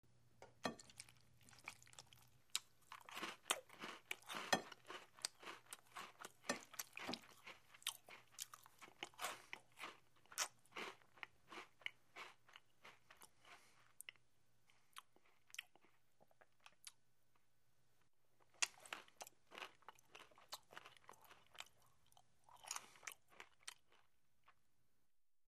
Звуки жевания
жуют пищу